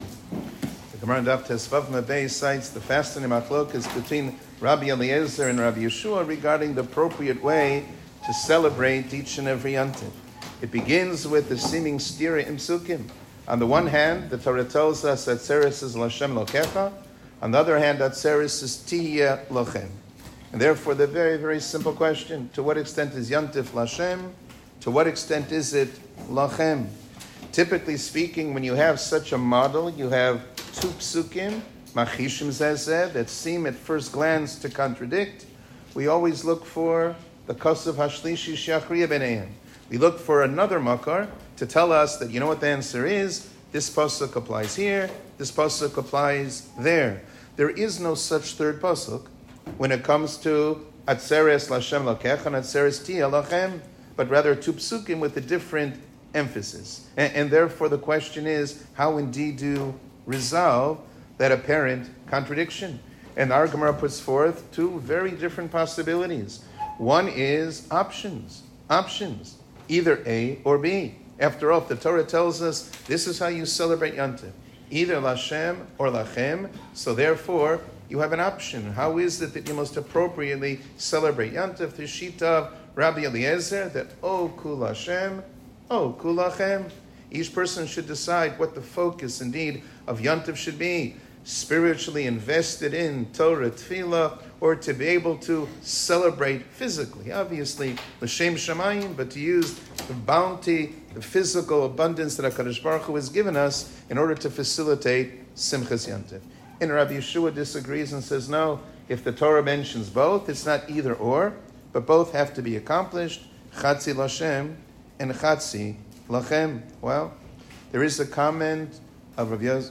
שיעור כללי - סעודות שבת ויום טוב